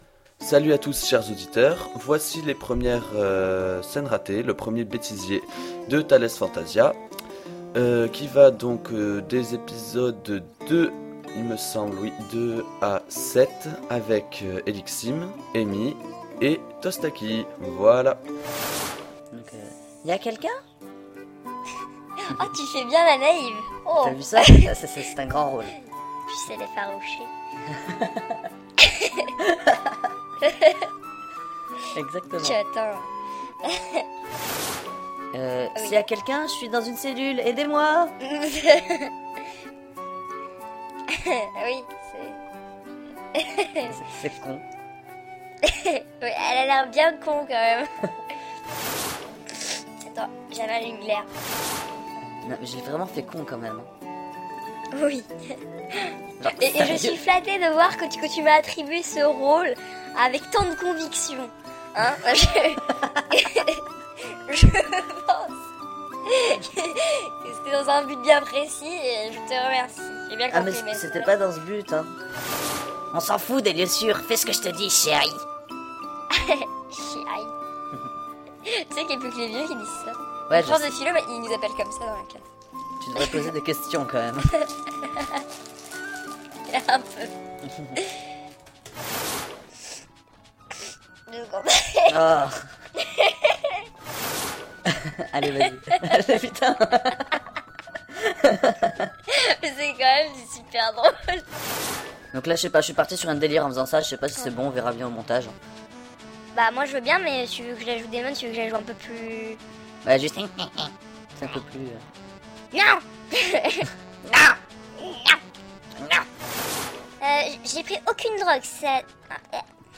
- B�tisier -
Betisier - Episodes 1 a 7 -.mp3